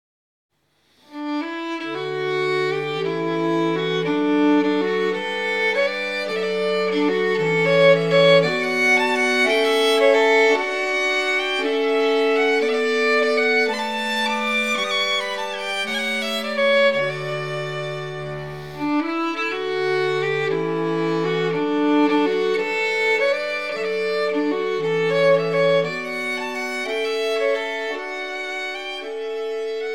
Norwegian folk music